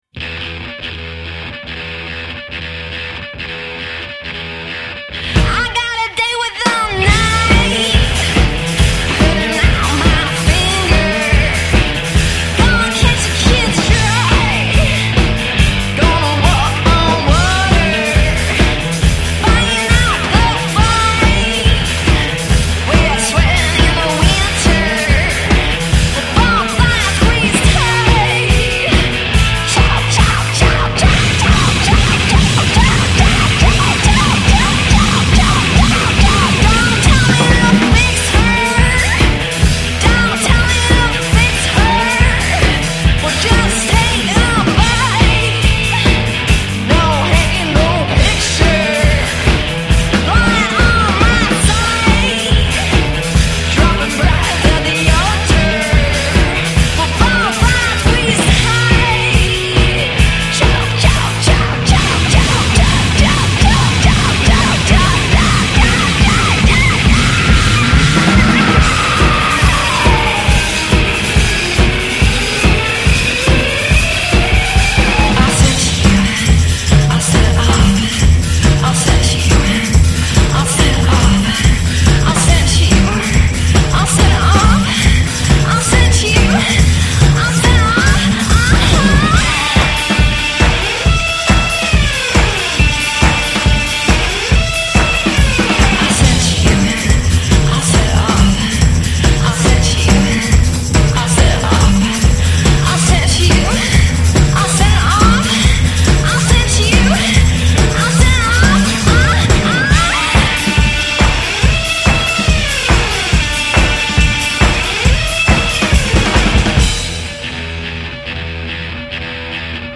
mi sembra si balli ancora benissimo